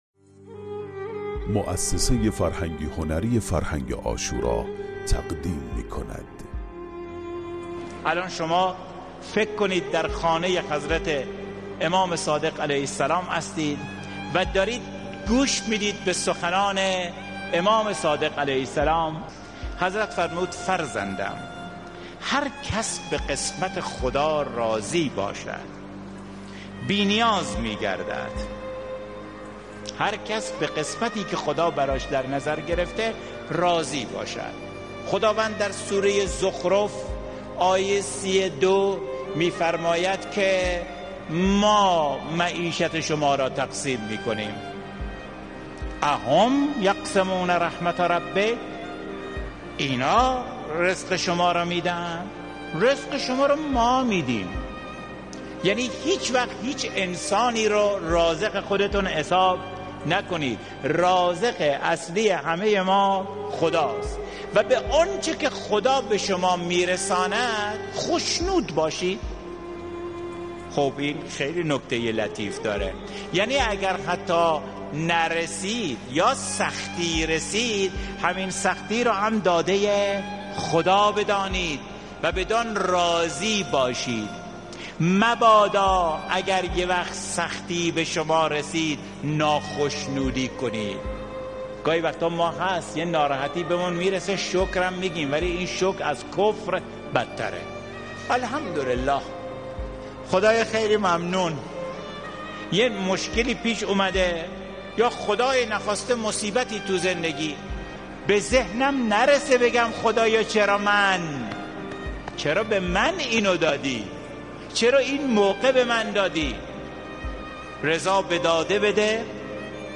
فایل صوتی زیر بخشی از سخنرانی